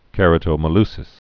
(kĕrə-tō-mə-lsĭs)